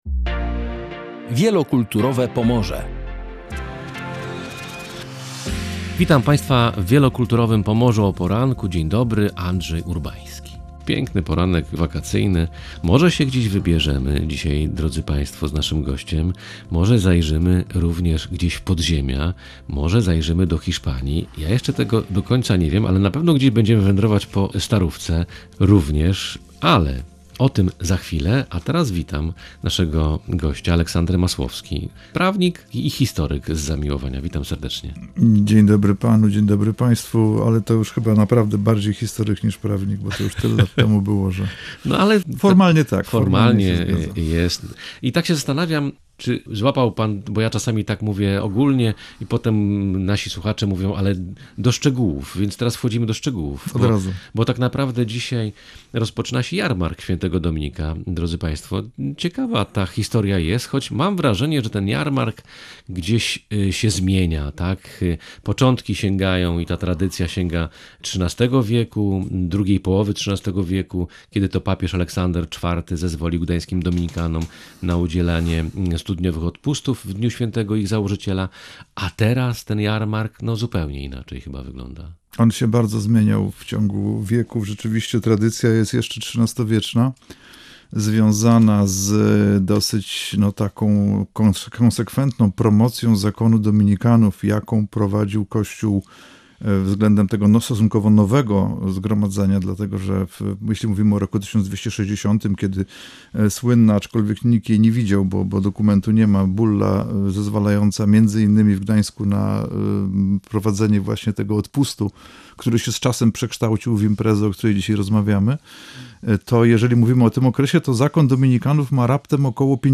W „Wielokulturowym Pomorzu” rozmawiamy o Dominiku Guzmanie oraz jarmarku, któremu patronuje.